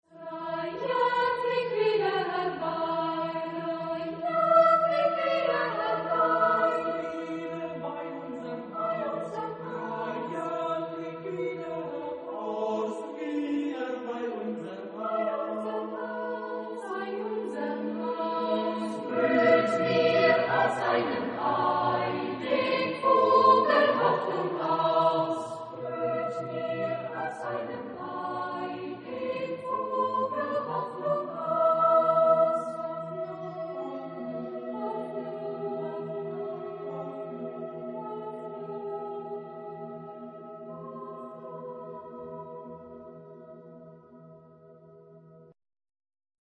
Genre-Style-Forme : Cycle ; Pièce chorale ; Profane
Type de choeur : SSAATB  (6 voix mixtes )
Tonalité : mi mineur